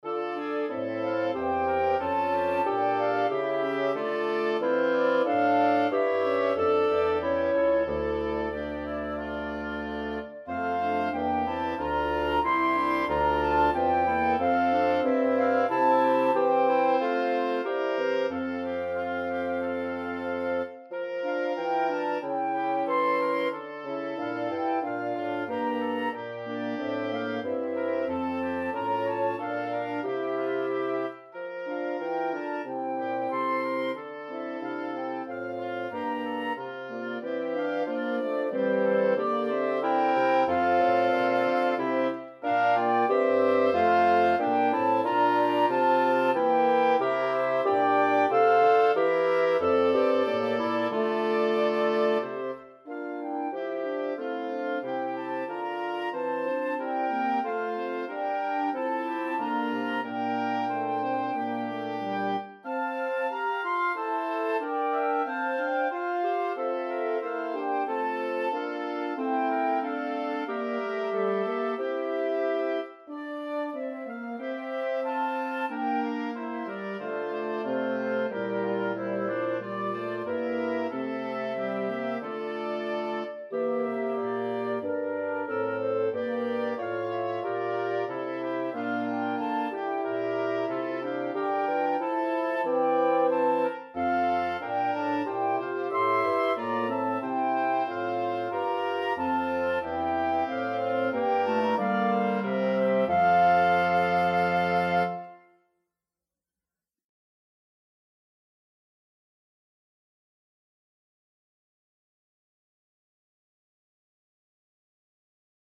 Kwintet voor houtblazers.